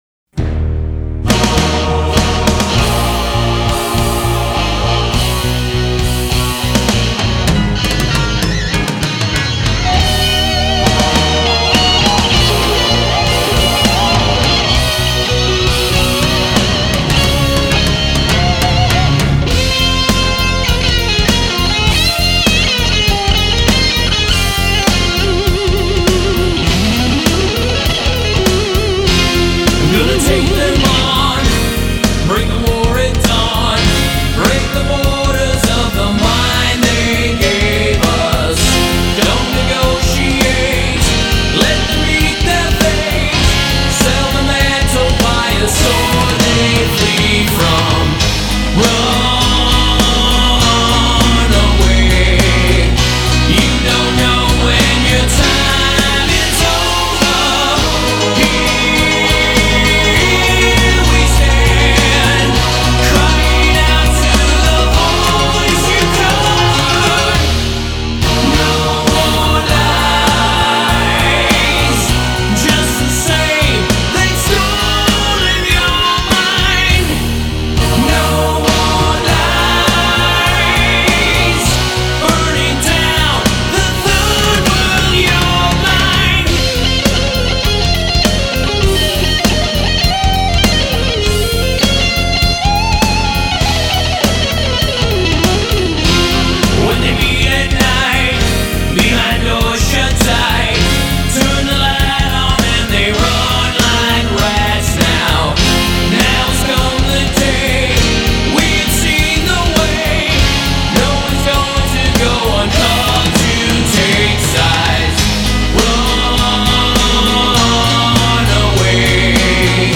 Guitars
Vocals
Keys, Bass, Drums